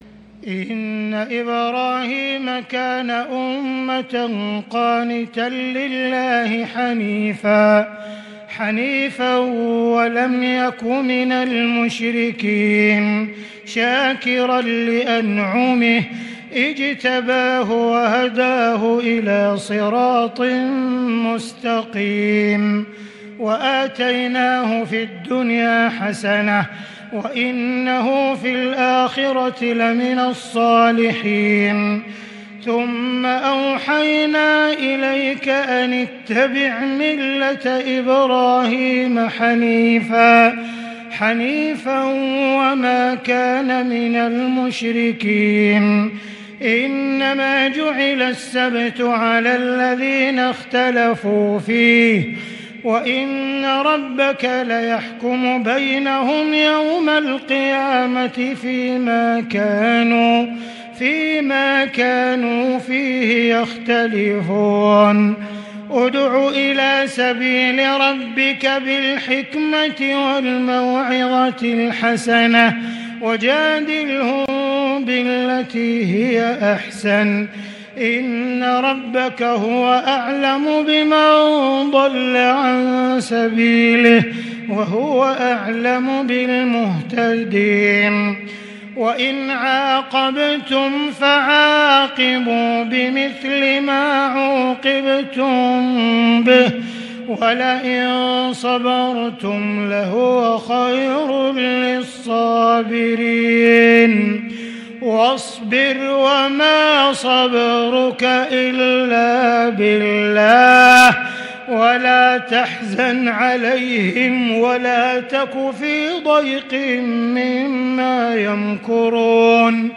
صلاة التراويح | ليلة ١٩ رمضان ١٤٤٢هـ | خواتيم النحل وفواتح الإسراء 1-52 | Taraweeh 19st night Ramadan 1442H > تراويح الحرم المكي عام 1442 🕋 > التراويح - تلاوات الحرمين